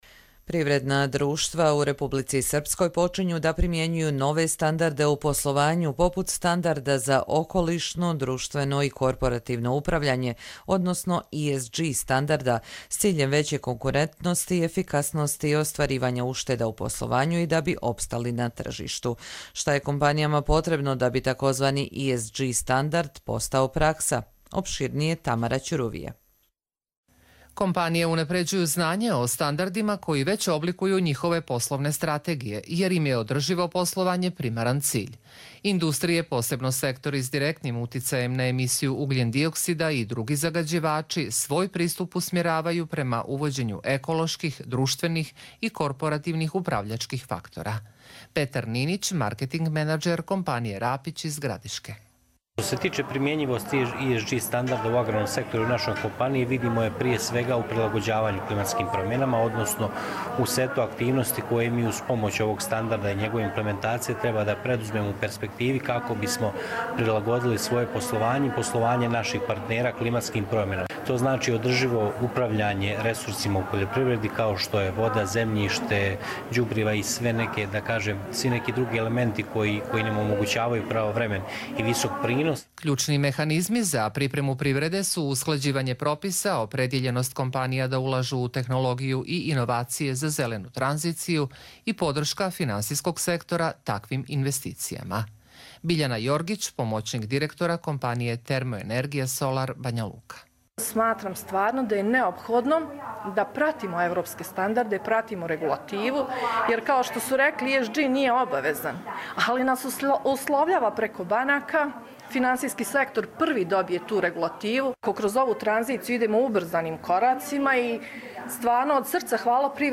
Радио репортажа